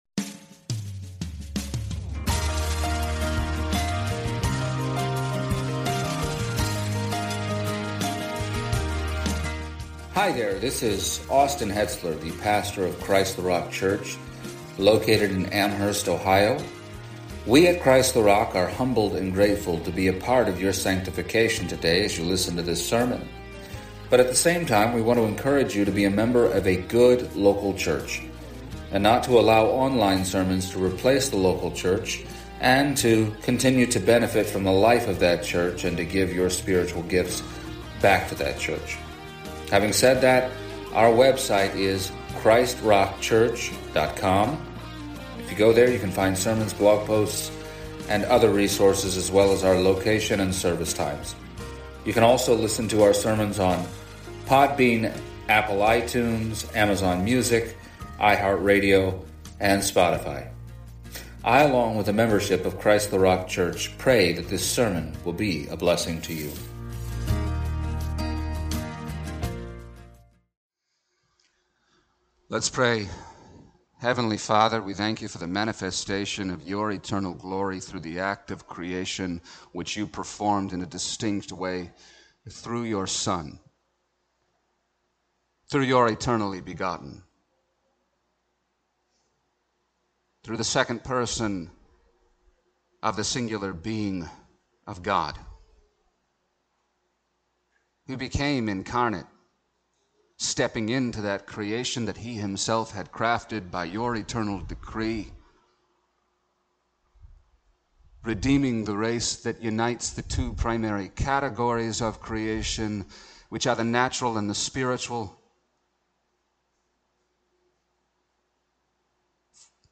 Hebrews 1:2 Service Type: Sunday Morning “The Father had a plan